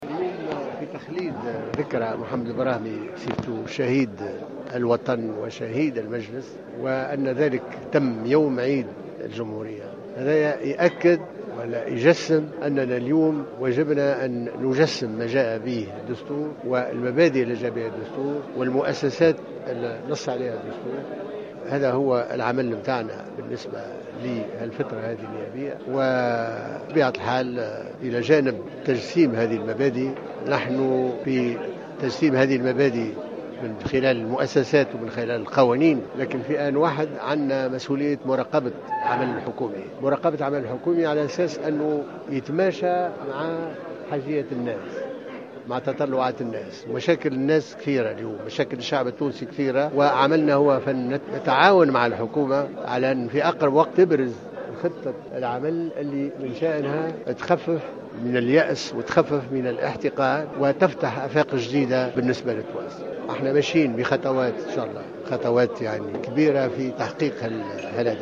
وقال الناصر في تصريح